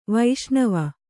♪ vaiṣṇava